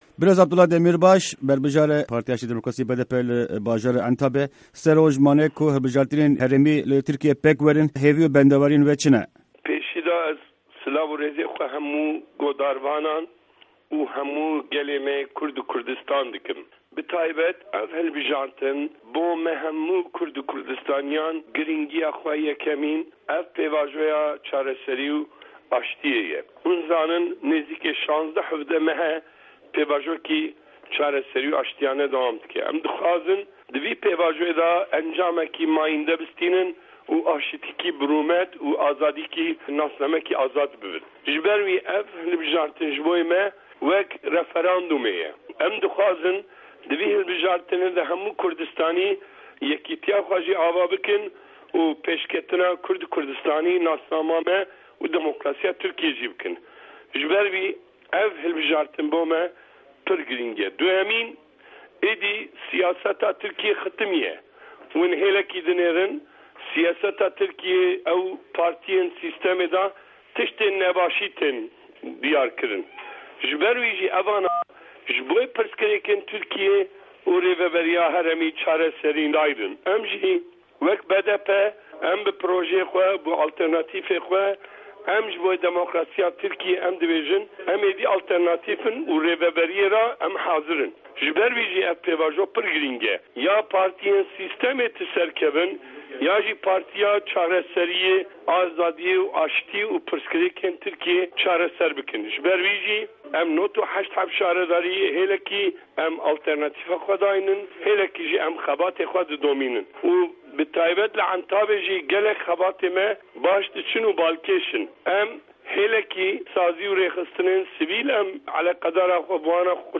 Di hevpeyvîna Dengê Amerîka de berbijarê Bajarvanîya Mezin ya Entabê ji BDP, Abdullah Demîrbaş li ser xebatên hilbijartinên herêmî li wî bajarî dike.